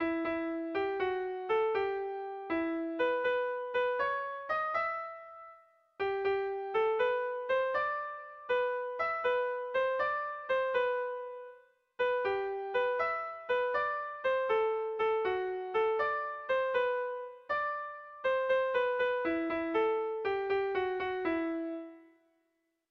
Irrizkoa
Zortziko txikia (hg) / Lau puntuko txikia (ip)
A-B-C-D